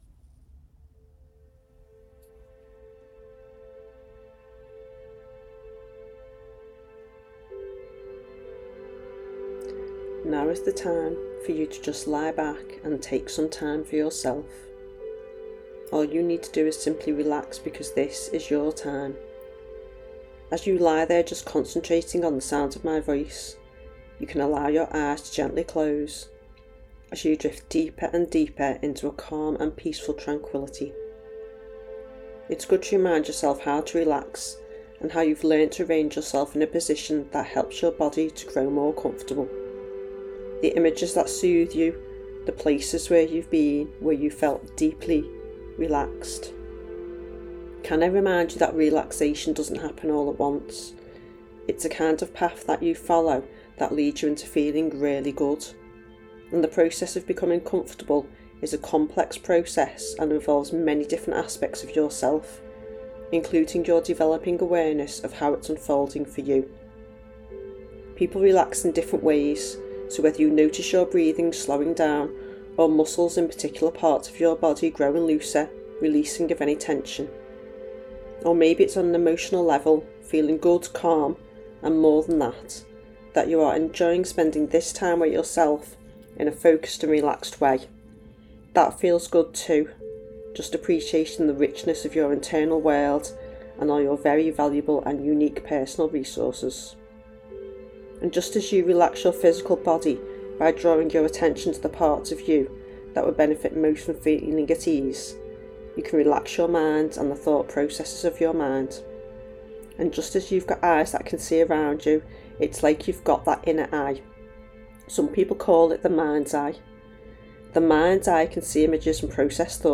This guided relaxation track is free to use, you can listen to it directly from this webpage. The best time to listen is before you go to sleep, it will help you relax and help you improve your sleep.